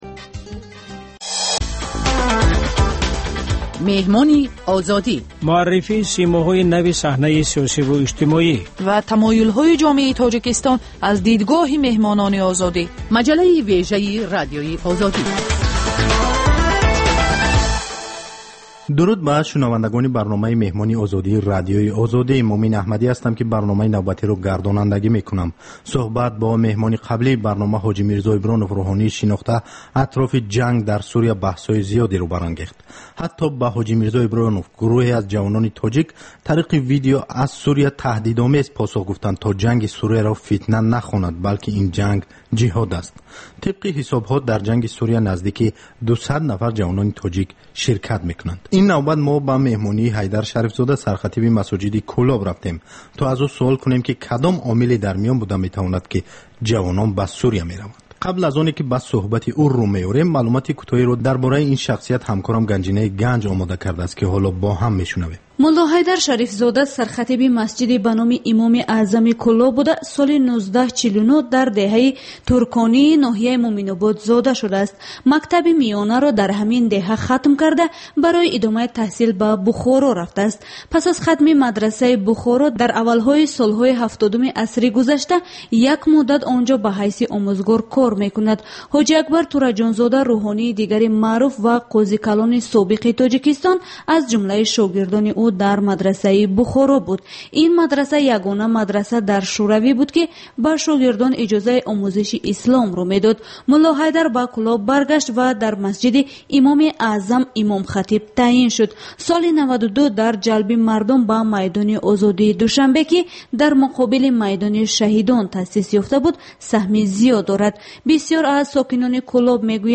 Сӯҳбати ошкоро бо чеҳраҳои саршинос ва мӯътабари Тоҷикистон бо пурсишҳои сангин ва бидуни марз.